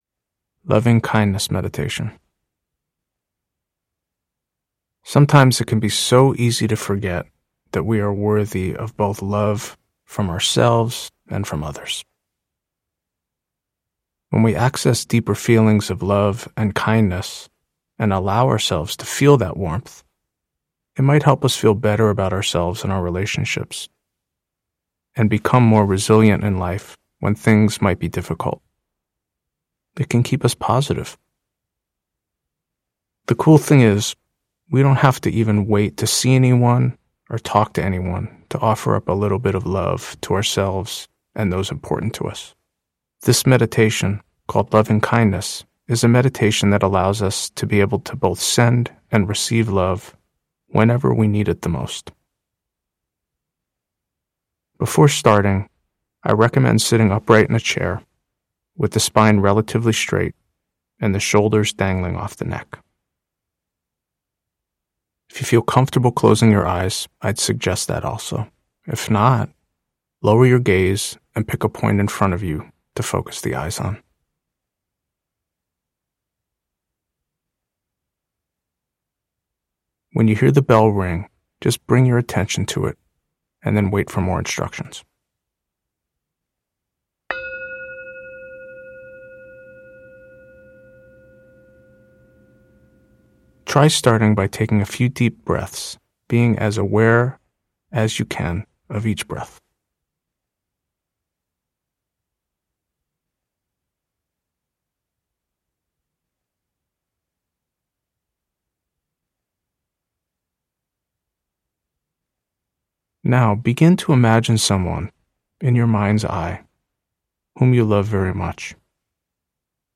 Guided Meditations & Mindfulness